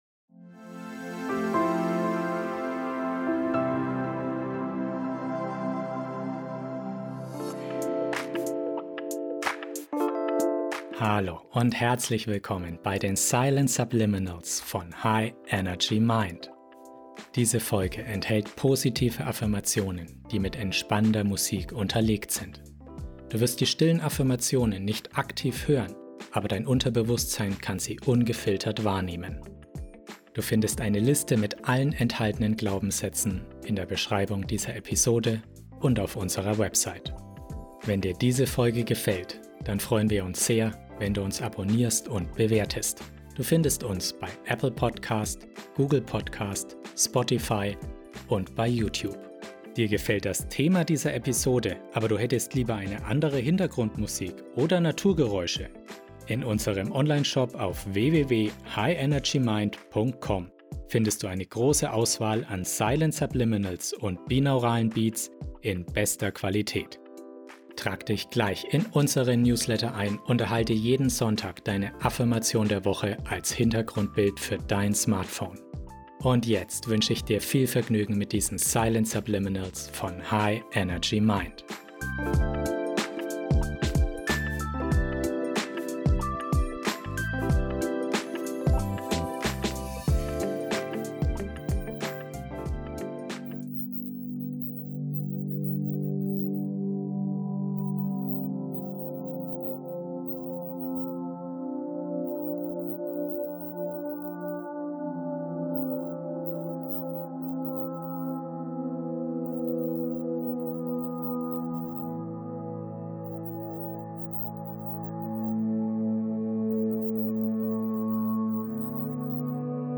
Beschreibung vor 4 Jahren Über diese Folge In dieser ermutigenden Folge im Silent Subliminals Podcast erfährst du, wie du dein Selbstvertrauen steigern kannst. Die beruhigende Musik in 432 Hz begleitet kraftvolle Silent Subliminals, die dein Unterbewusstsein erreichen und dein Selbstvertrauen stärken können.
Silent Subliminals enthalten unhörbare Botschaften, die direkt an das Unterbewusstsein gesendet werden, um Verhaltensänderungen oder Verbesserungen in bestimmten Lebensbereichen zu bewirken.